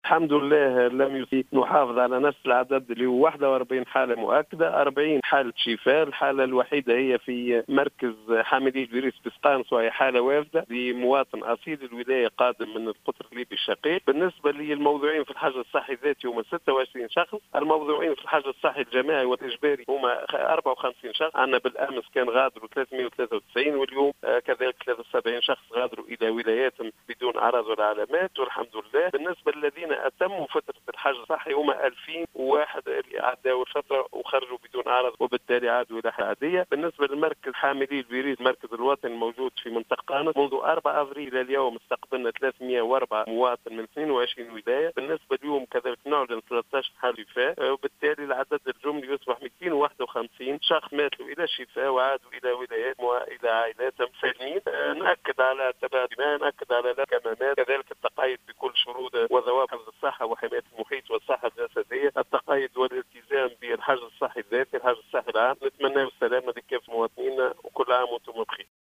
قال المدير الجهوي للصحة بالمنستير، المنصف الهواني في تصريح اليوم لـ"الجوهرة أف أم" إن الوضع الصحي في الجهة مستقر، وإنه لم يتم تسجيل اي إصابة جديدة بفيروس كورونا.